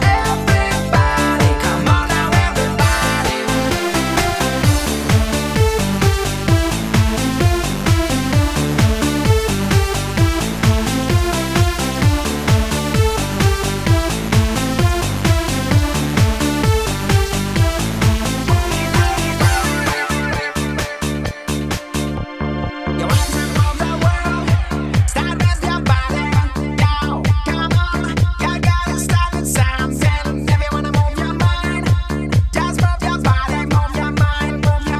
Dance Pop Britpop Rock
Жанр: Поп музыка / Рок / Танцевальные